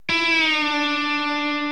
How to Do a Pre Bend
Pre-bending simply means that you fret and fully bend the note before picking the string. After the string is picked, you will release the string in a controlled manner back to its original position.
The idea here is that you begin with a note that has a higher pitch and end up with a lower pitched note.
PreBend.mp3